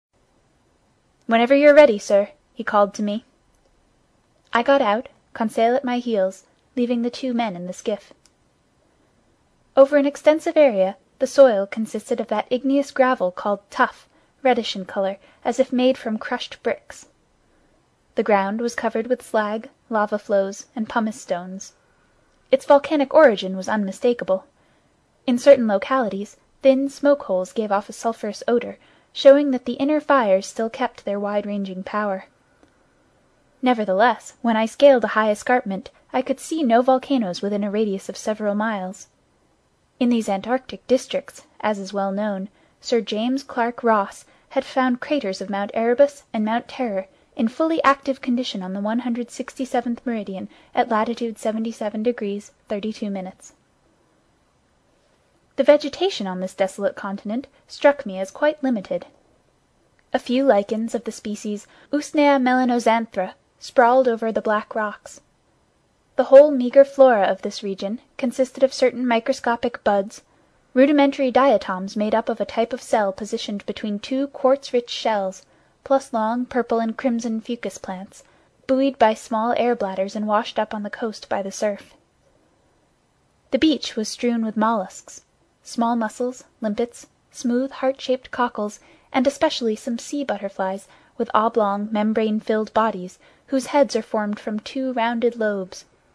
英语听书《海底两万里》第444期 第27章 南极(3) 听力文件下载—在线英语听力室
在线英语听力室英语听书《海底两万里》第444期 第27章 南极(3)的听力文件下载,《海底两万里》中英双语有声读物附MP3下载